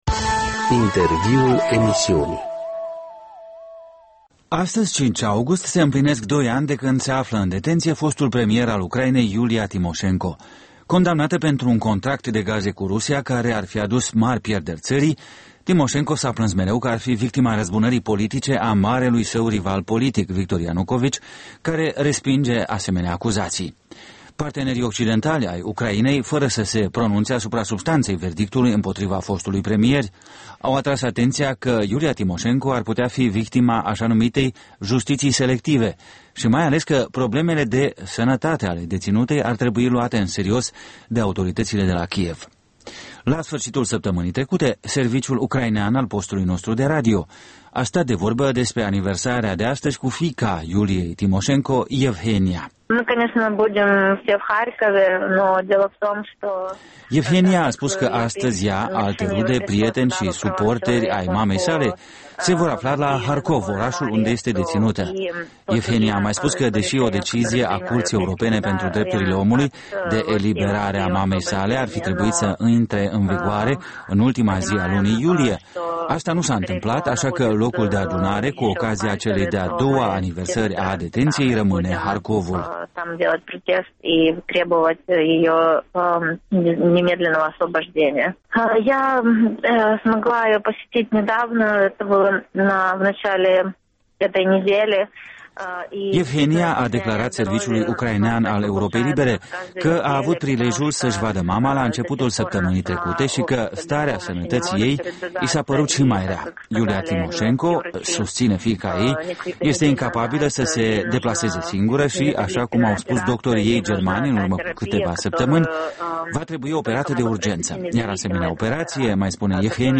Un interviu la împlinirea a doi ani de la intrarea în detenție a Iuliei Timoșenko